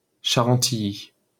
Charentilly (French pronunciation: [ʃaʁɑ̃tiji]